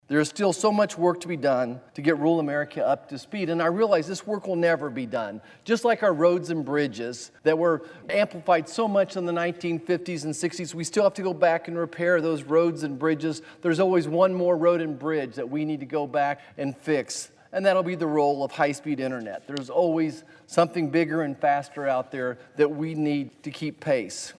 Sen. Roger Marshall also spoke on the Senate floor earlier this month about the need for continued broadband expansion into rural areas.